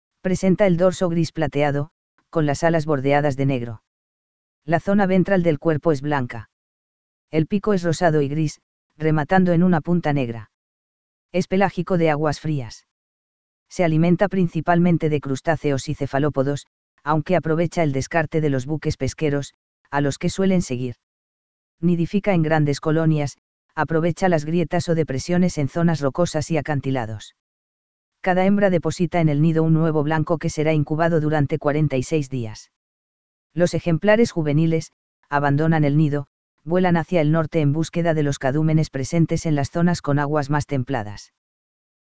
Petrelplateado.mp3